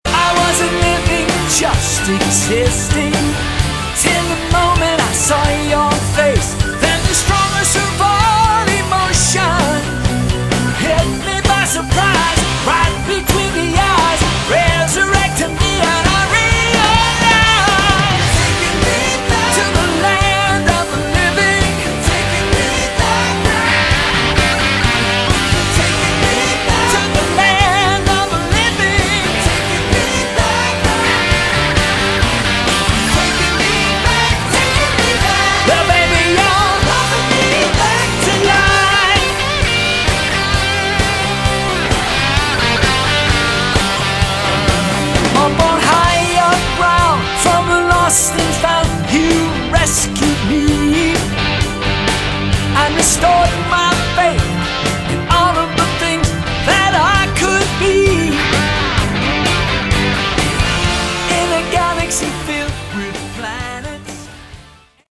Category: AOR
lead and backing vocals, keyboards, synth bass
guitar, bass, backing vocals